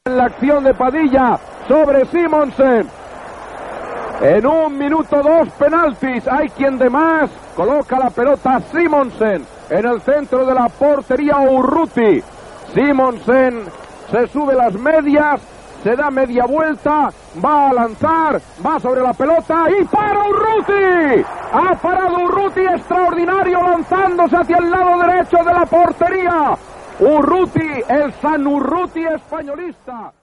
Partit de la lliga masculina de futbol entre el Futbol Club Barcelona i el Real Club Deportivo Español. Narració del penalti a favor del Barça.
Esportiu